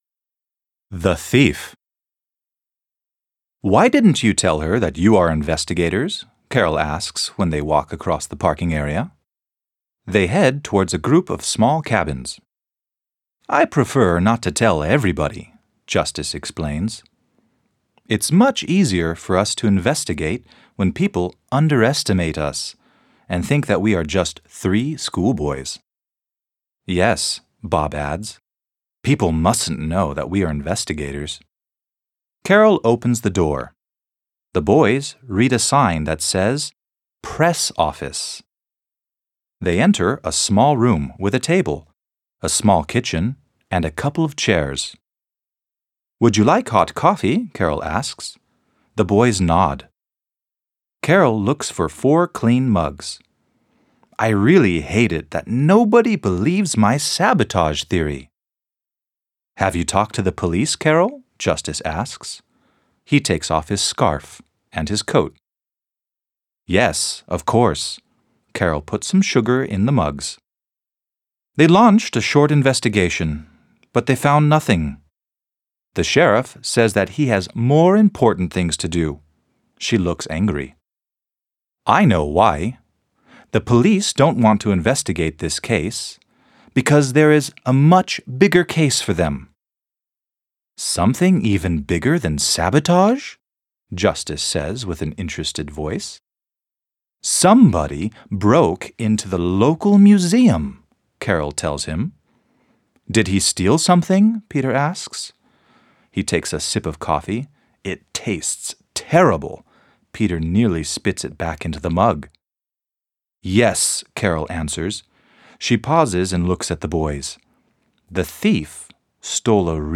PONS Die drei ??? Fragezeichen Arctic Adventure - Kari Erlhoff - Hörbuch